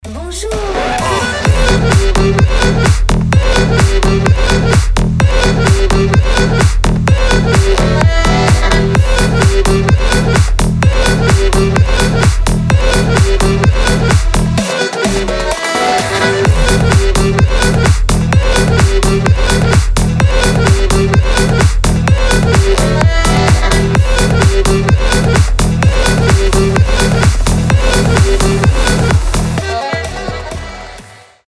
• Качество: 320, Stereo
громкие
EDM
Big Room
Melbourne Bounce
Стиль: биг рум, Мельбурнский баунс